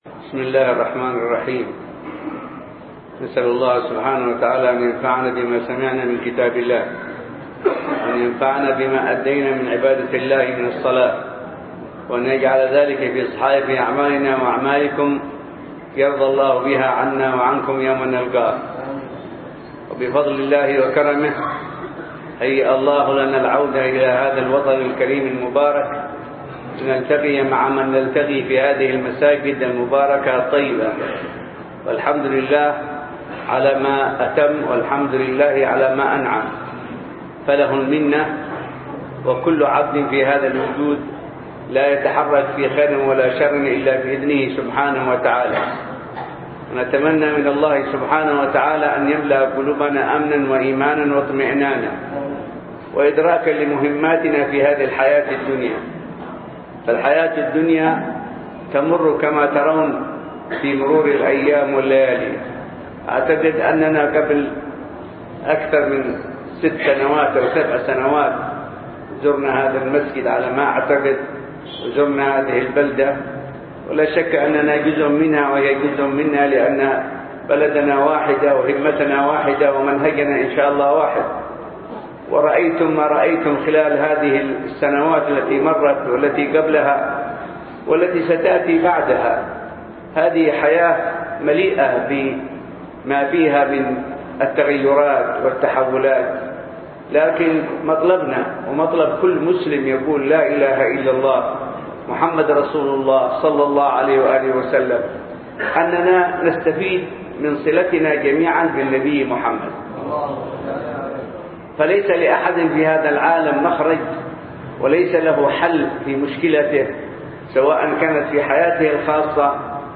محاضرة
بجامع الحوطة – حضرموت